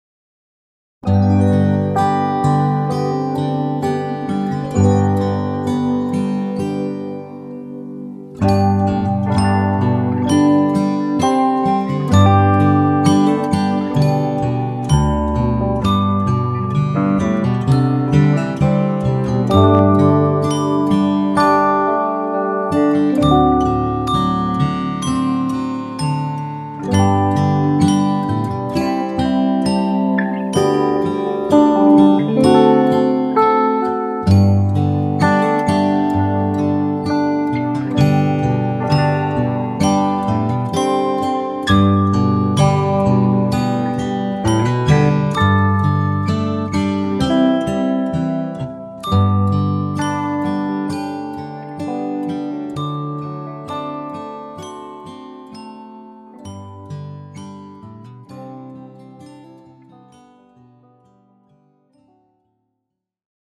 Latviešu tautas dziesma flautai Play-along.
Spied šeit, lai paklausītos Demo ar melodiju